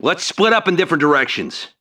、 分类:维和步兵语音 您不可以覆盖此文件。
CHAT_AlliedSoldier57.wav